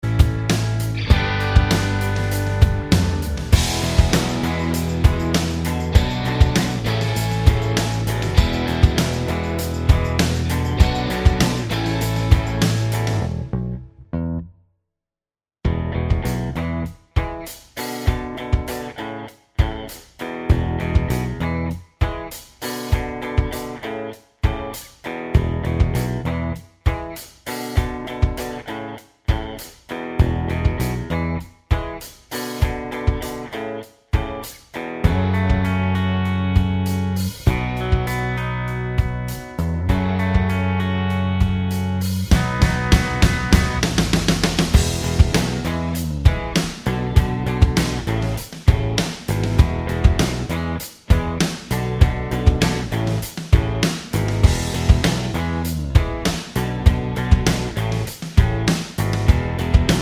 no Backing Vocals Rock 3:18 Buy £1.50